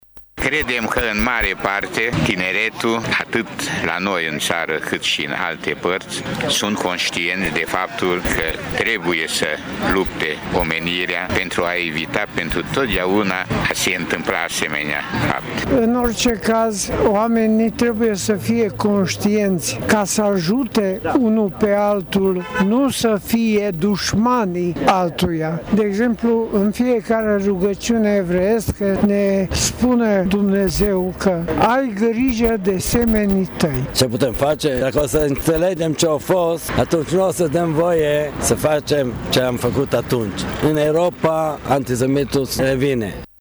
Trei dintre urmașii supraviețuitorilor lagărelor naziste au declarat că tânăra generație trebuie educată pentru a nu uita cele petrecute cu peste 70 de ani în urmă: